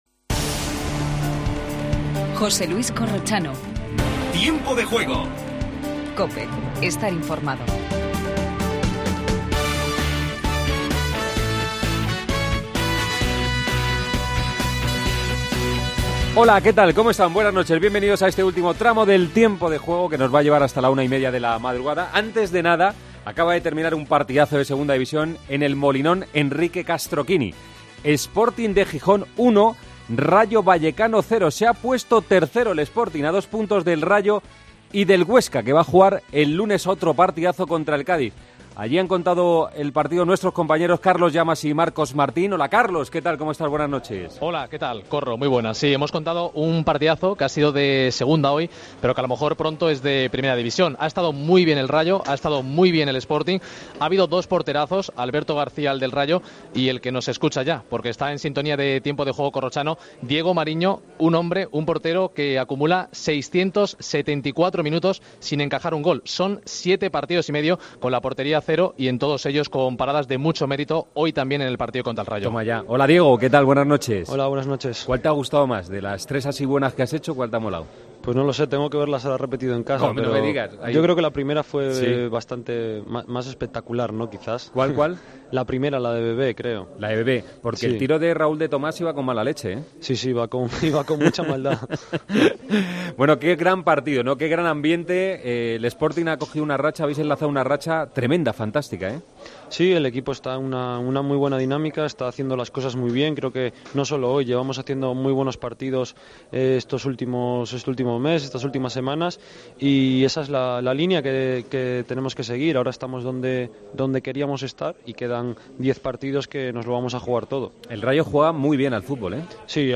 El Sporting ganó 1-0 al Rayo Vallecano en el partido más importante de la Liga 123. Entrevista a Diego Mariño.